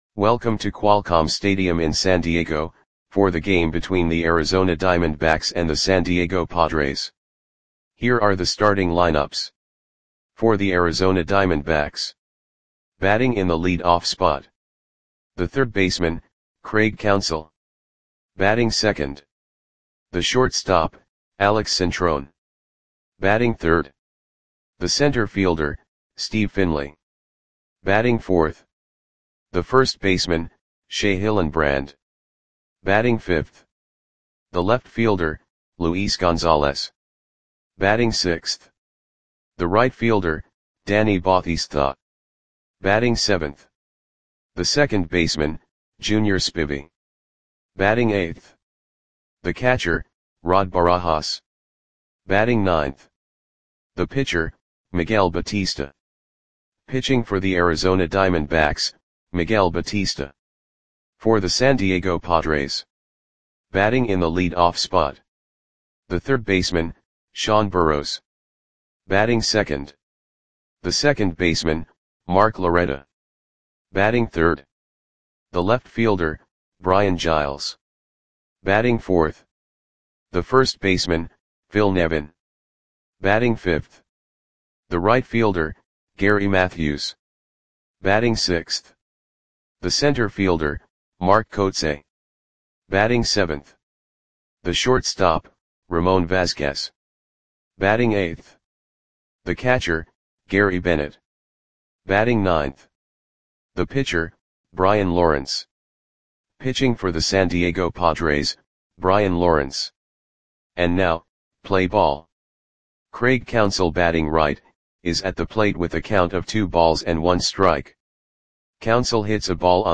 Lineups for the San Diego Padres versus Arizona Diamondbacks baseball game on September 3, 2003 at Qualcomm Stadium (San Diego, CA).
Click the button below to listen to the audio play-by-play.